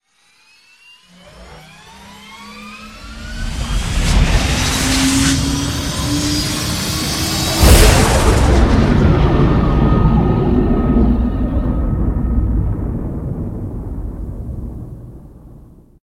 launch6.ogg